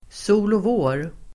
Ladda ner uttalet
Uttal: [²so:låv'å:r]